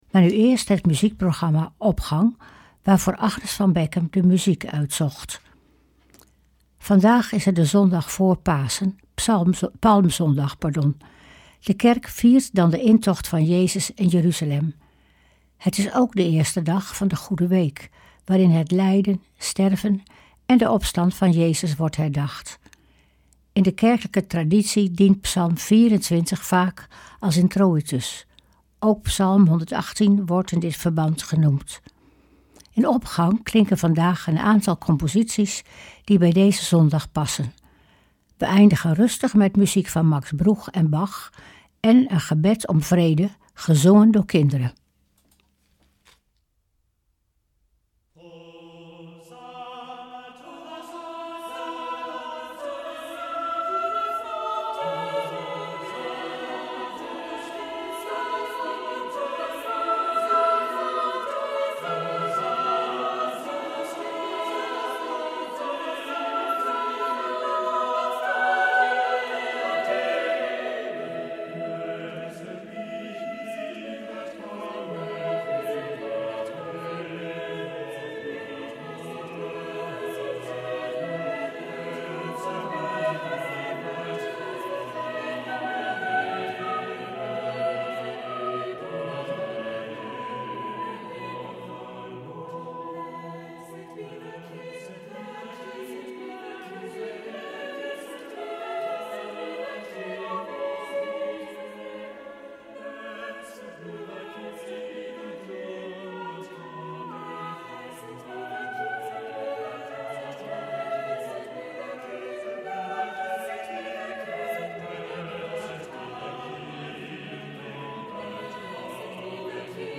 gezongen door kinderen.